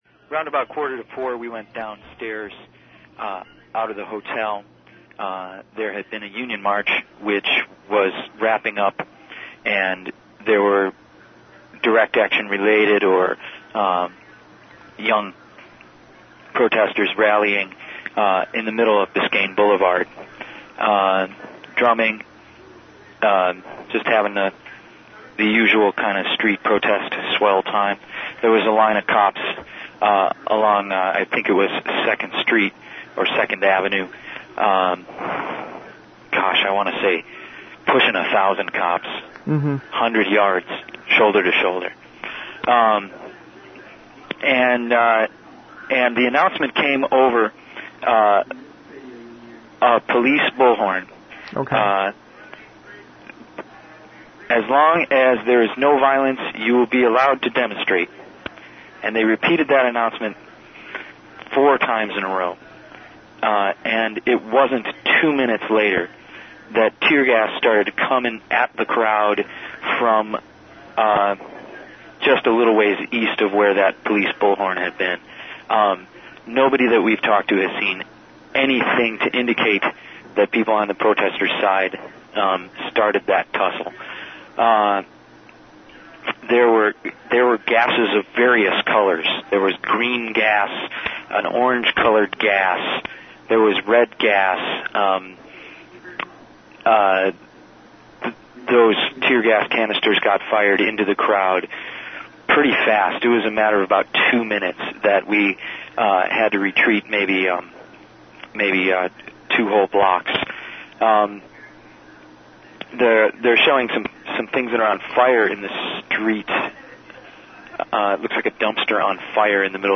Miami Audio Report: First Hand Account Of Police Push Against Protestors Current rating: 0
He then watched the police push from inside the hotel and filed this report with us back in the IMC.